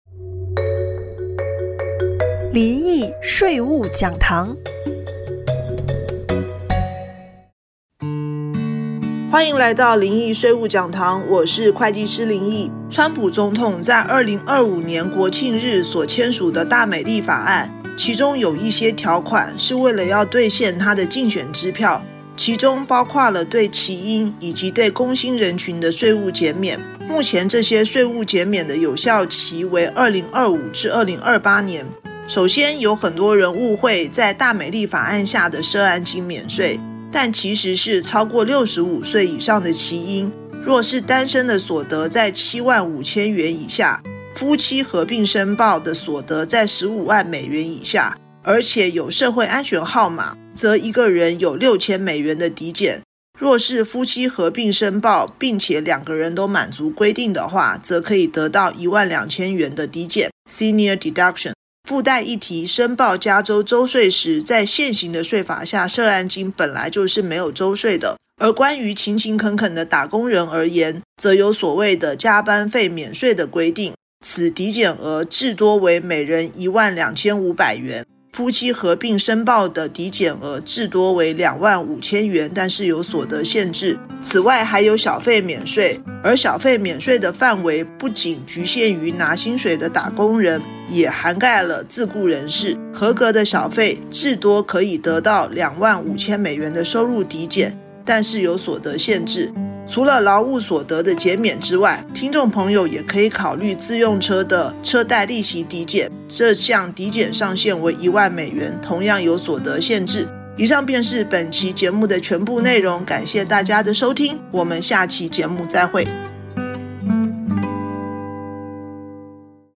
電台訪談